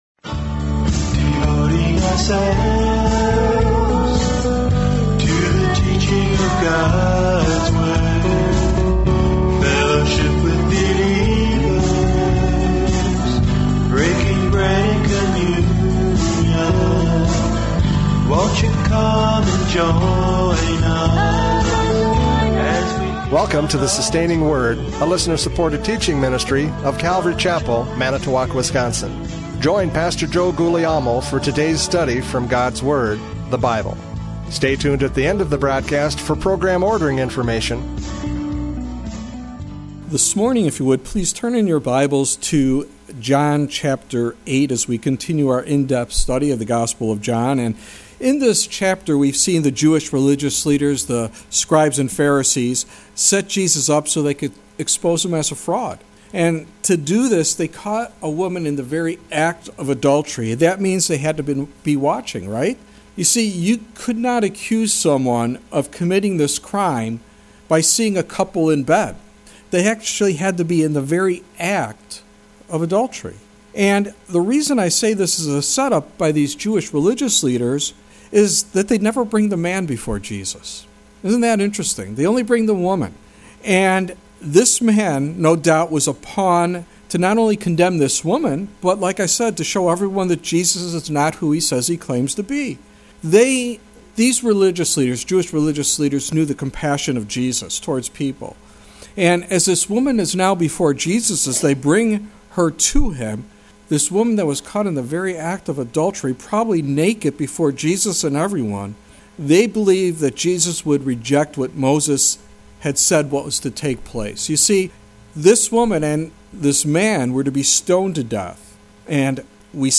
John 8:21-30 Service Type: Radio Programs « John 8:12-20 Light and Darkness!